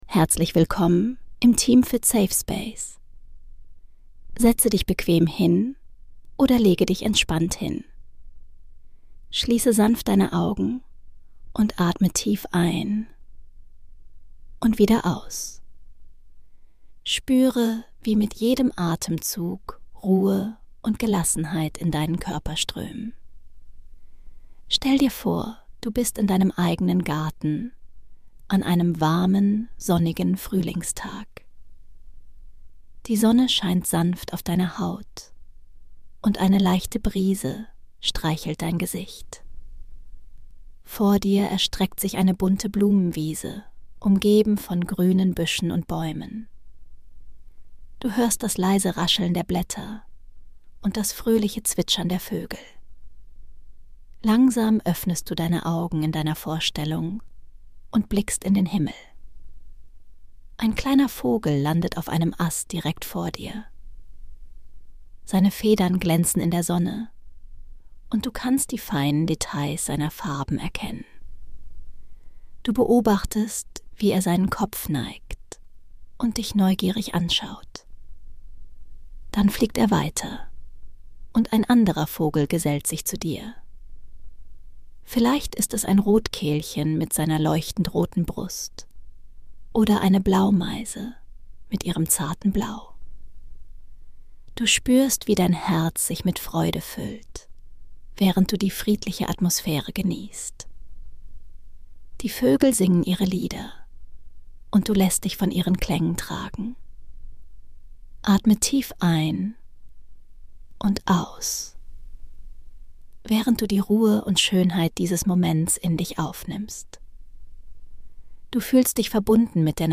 Entspannende Traumreise zur Beobachtung von Vögeln im Garten.
Genieße Ruhe und Naturklänge.